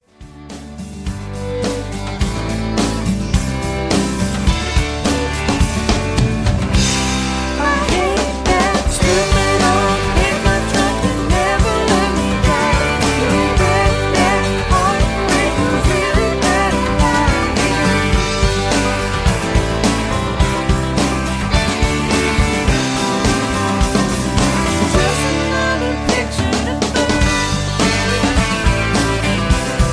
Key-G) Karaoke MP3 Backing Tracks
mp3 backing tracks